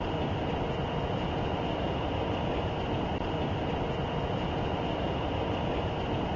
movingblockloop.wav